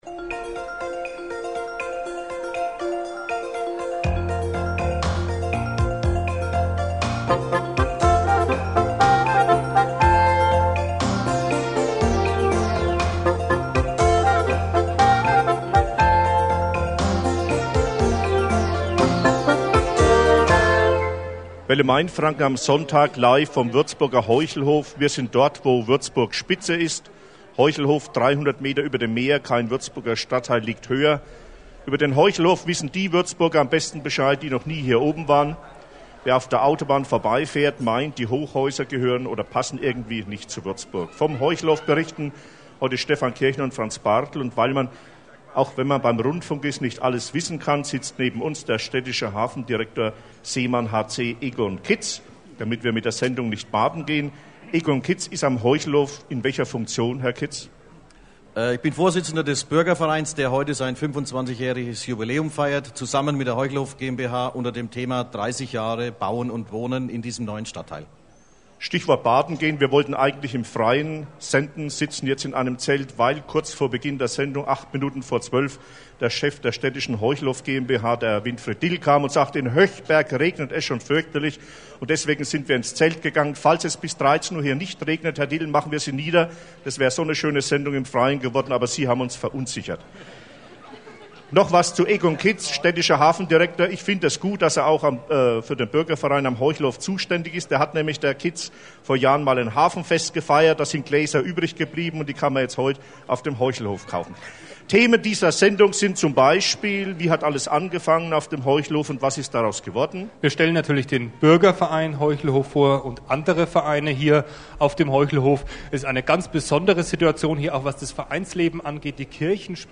Im Jahr 1992 feierte der Bürgerverein Heuchelhof sein 25-jähriges Bestehen . Zum damaligen Jubiläum war der Bayerische Rundfunk mit einem Übertragungswagen und dem bekannten Mittagsmagazin "Welle Mainfranken" (heute "Mittags in Mainfranken") für eine Live-Sendung im "Gut Heuchelhof" zu Gast.
Sie berichten ausführlich über die Anfänge und das Leben auf "dem Berg". Die Musik habe ich aus urheberrechtlichen Gründen aus der Aufnahme entfernt.